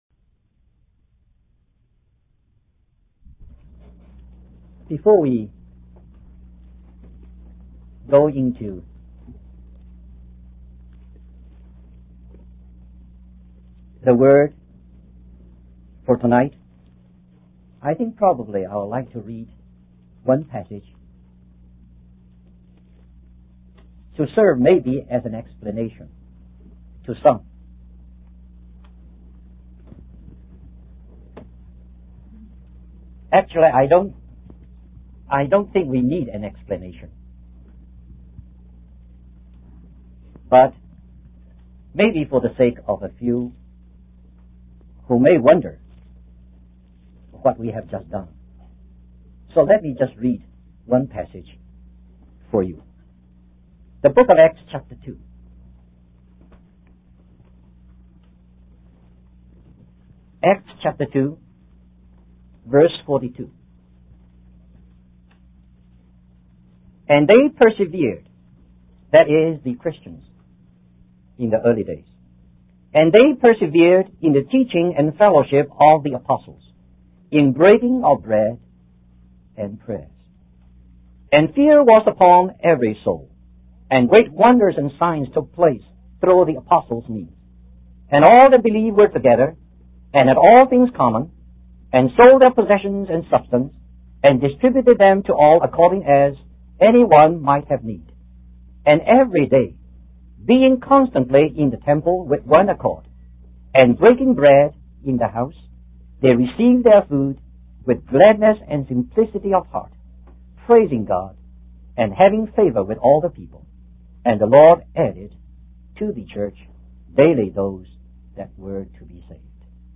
In this sermon, the speaker emphasizes the importance of bearing witness and reporting the truth. They explain that in order for something to be considered a testimony, it must be seen and heard by the person giving the testimony.